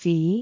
speech
syllable
pronunciation
fi2.wav